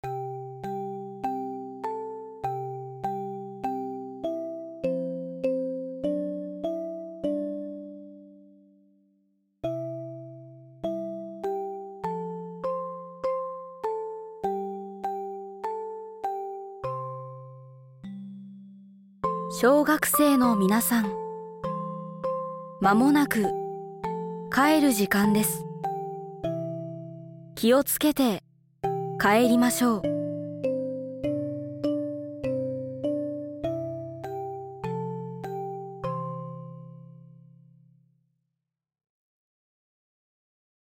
小学生帰宅時刻案内放送について
・令和６年６月１日（土）より市内全域の防災行政無線屋外スピーカーを利用し、小学生の帰宅時刻を案内しています。
「夕焼け小焼け」の曲とともに「小学生の皆さん、間もなく帰る時間です。気をつけて帰りましょう。」をアナウンス。